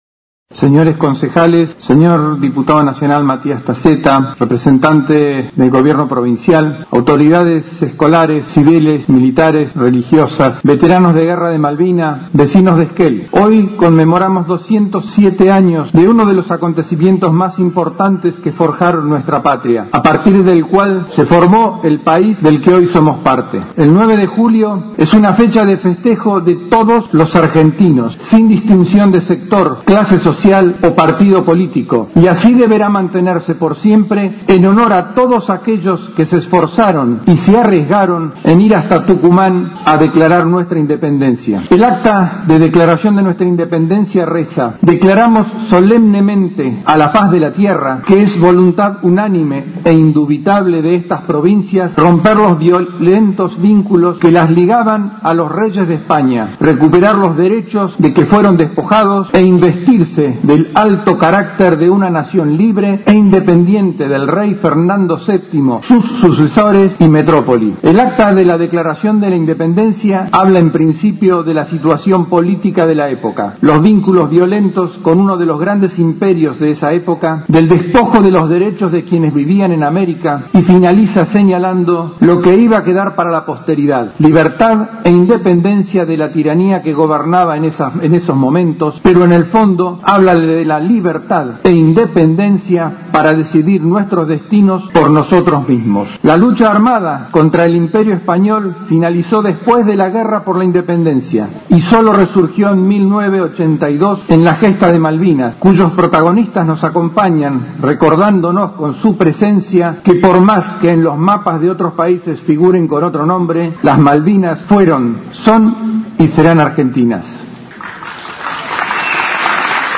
En el Gimnasio Municipal se realizó el acto central, por el 9 de Julia al cumplirse 207° años de la Independencia Argentina.
El único orador en el acto fue el Intendente Ongarato.